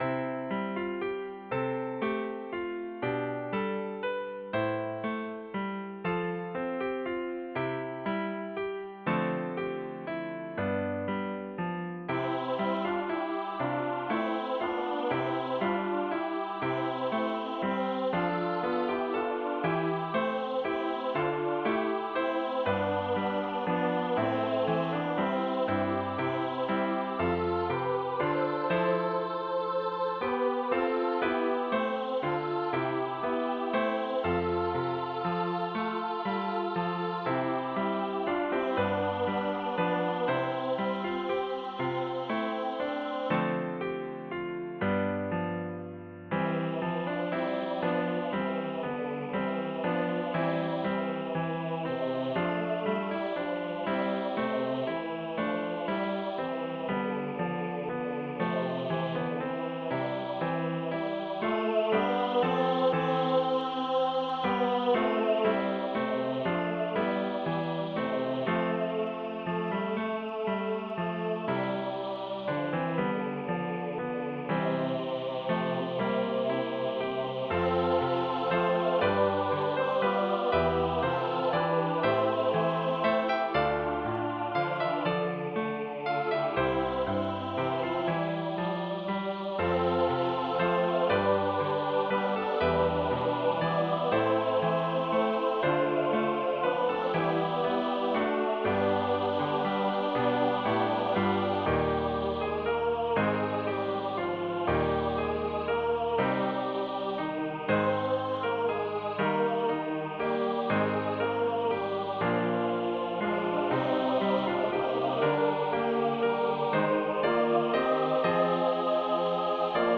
SATB
Je pensais que ce serait amusant de créer un arrangement pour chœur SATB de l'hymne numéro 1030 "Close as a Quiet Prayer".Une partition de 2 pages pour les parties vocales est incluse ainsi qu'un fichier sonore généré par ordinateur.
Voicing/Instrumentation: SATB